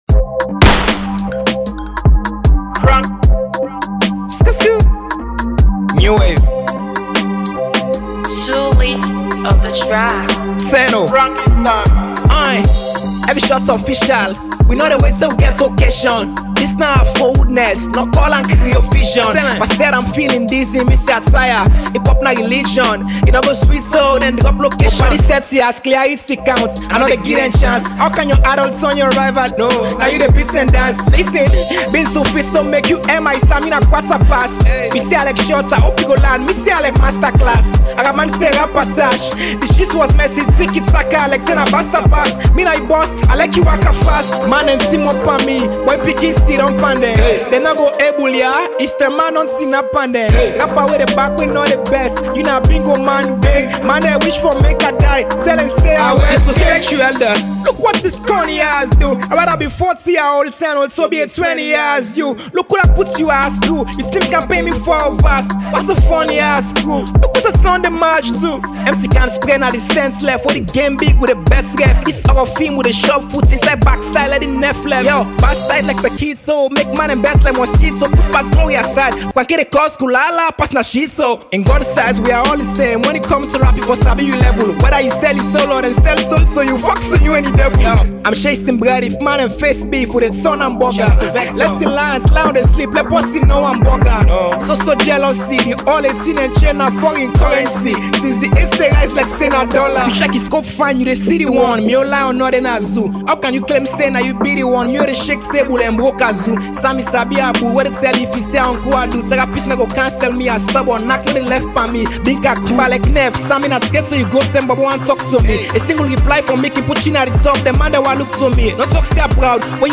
with hardcore instrumentals
Hip-Hop freestyle with bars and continuous rap flow